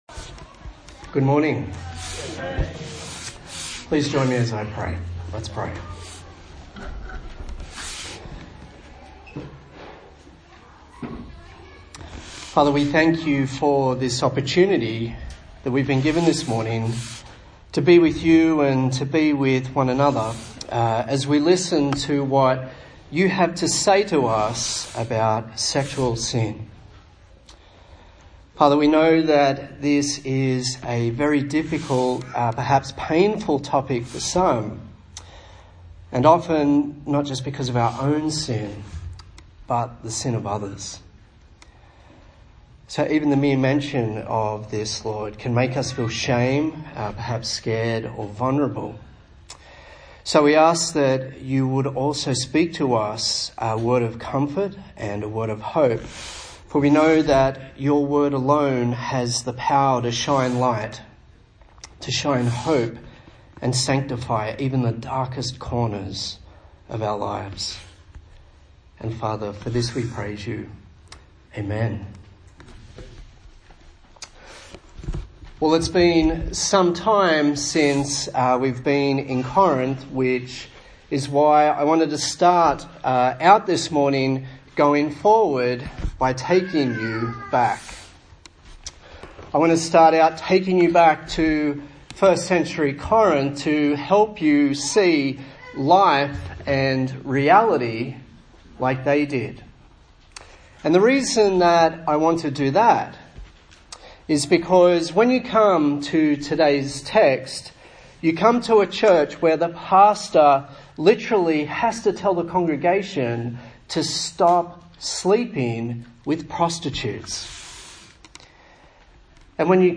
1 Corinthians Passage: 1 Corinthians 6:12-20 Service Type: Sunday Morning